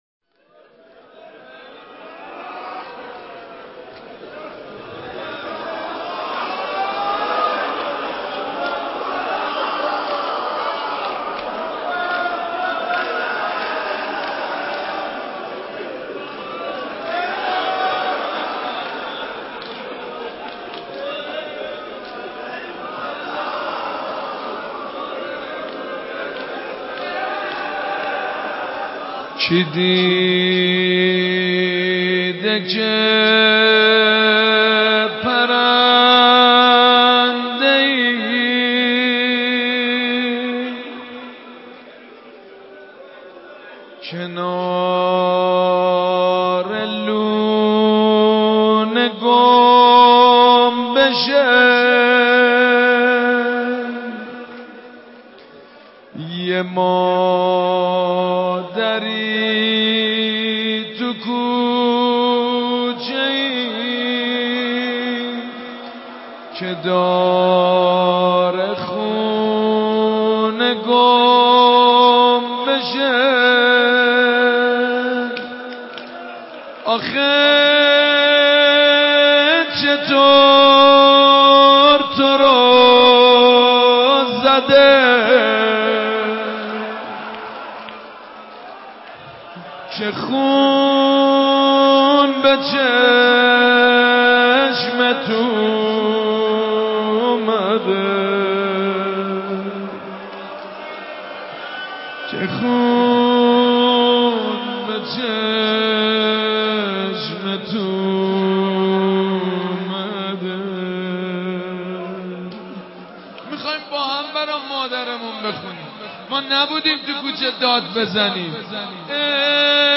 صوت مداحی و روضه خوانی سید مجید بنی فاطمه ایام فاطمیه منتشر می شود.